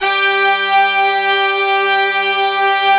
G4 POP BRASS.wav